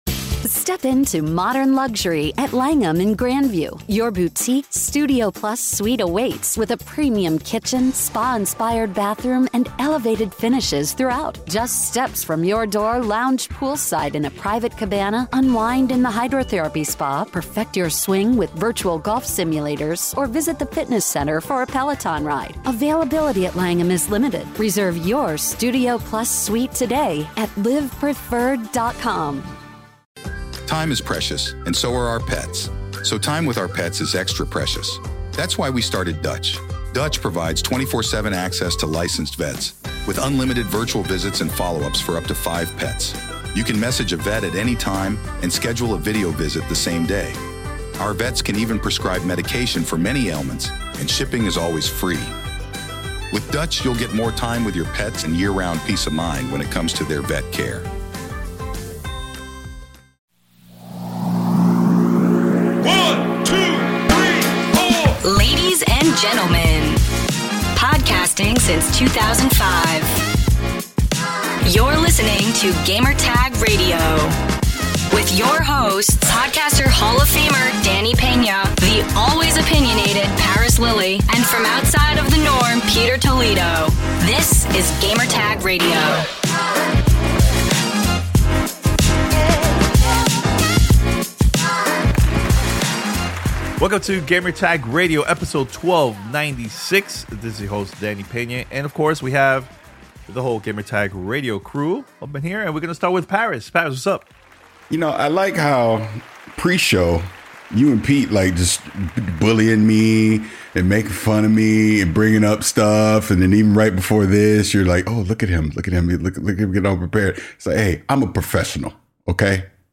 PS5 Slim Leak, Baldur's Gate 3 and Lords of The Fallen interview